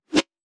weapon_bullet_flyby_11.wav